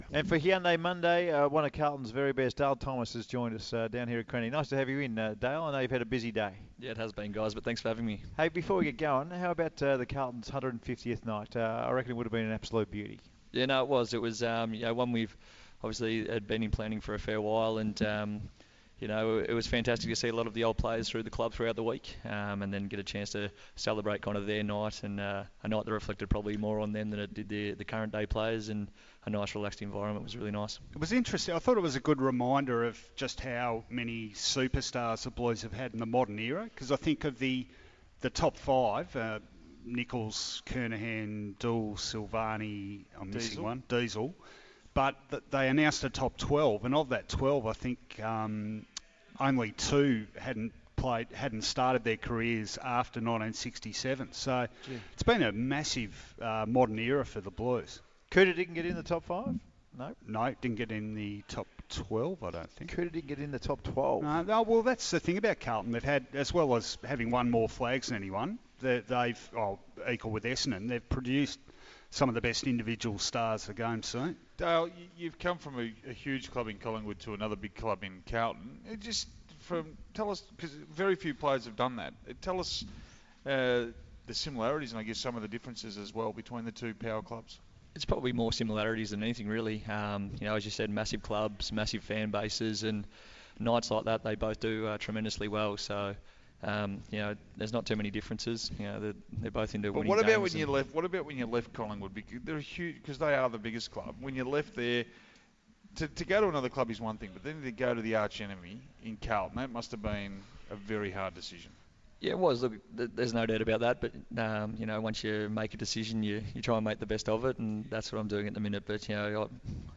for a chat on Monday, June 17th.